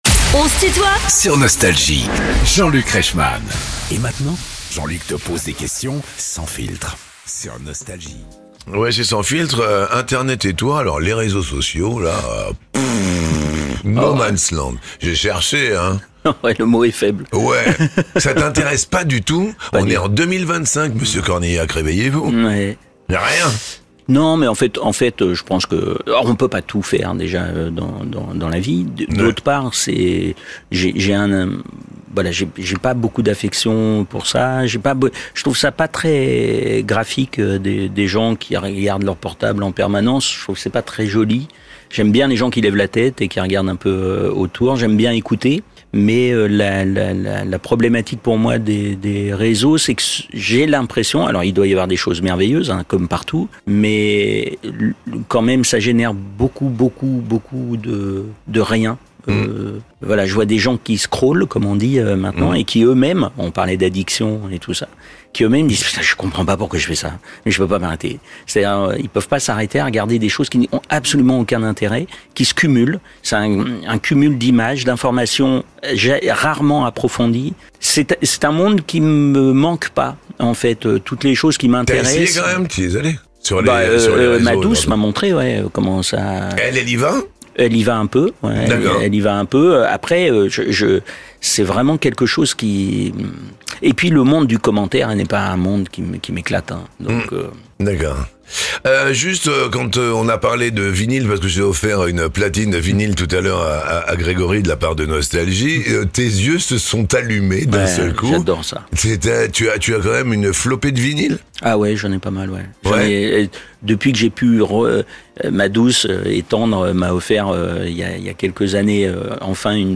Invité de "On se tutoie ?..." sur Nostalgie, Clovis Cornillac répond aux questions sans filtre de Jean-Luc Reichmann
Les interviews